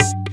Bongo 12.wav